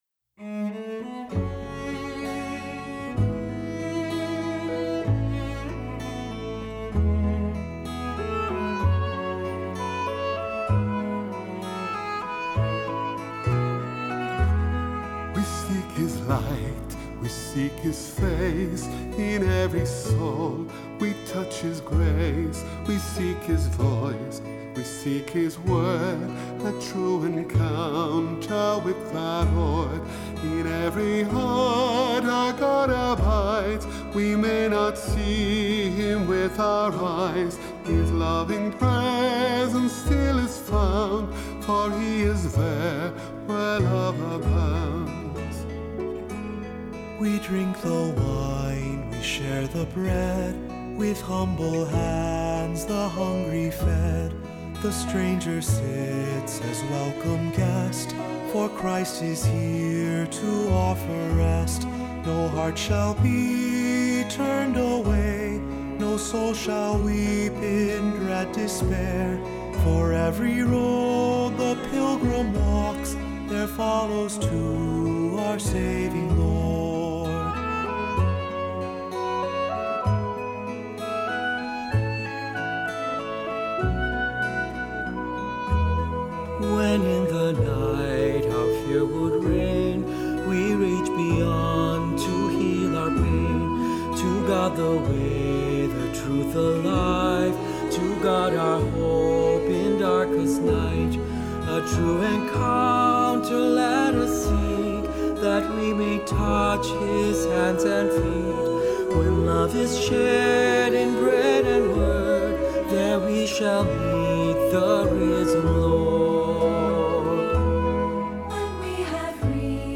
Accompaniment:      Keyboard
Music Category:      Christian